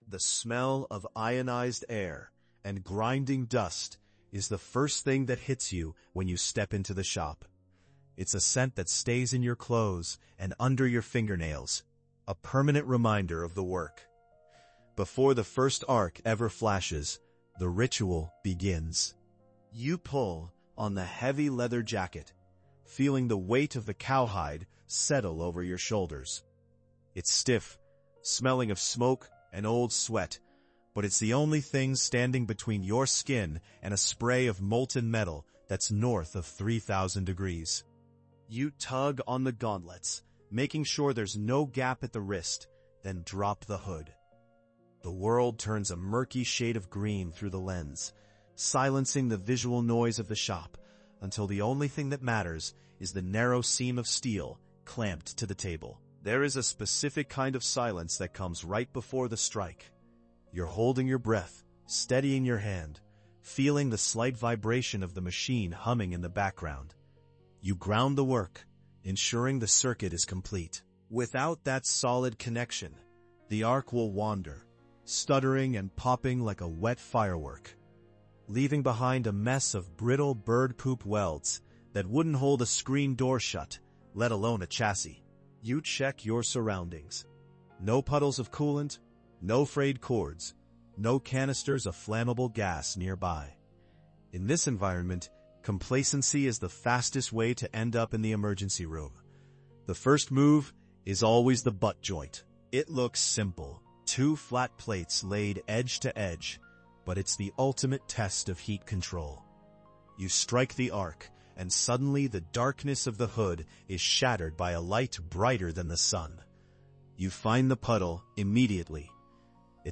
As the arc strikes, we break down the fundamental techniques behind three essential welds: the butt joint, the lap joint, and the T-joint. You’ll hear the "frying bacon" crackle of a perfect weld and learn the nuances of heat control, from achieving the "stack of dimes" appearance to ensuring deep penetration and avoiding common pitfalls like cold laps or brittle beads.